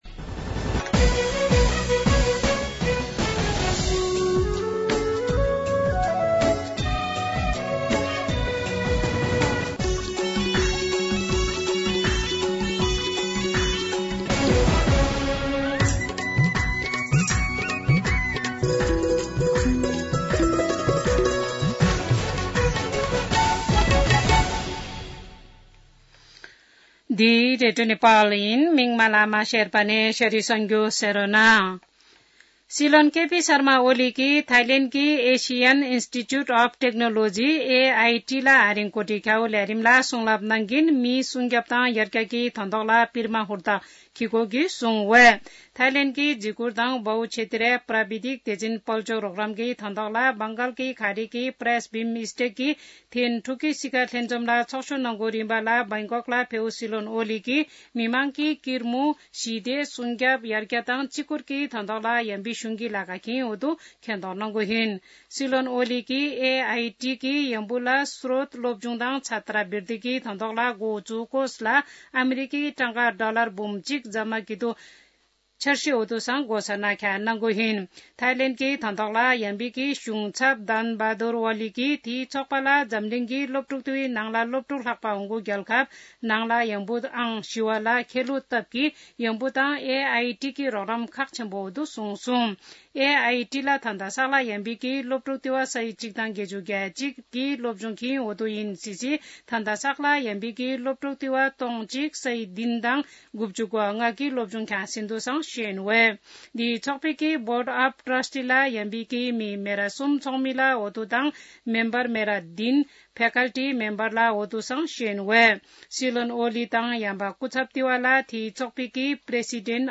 शेर्पा भाषाको समाचार : २१ चैत , २०८१
Sherpa-News.mp3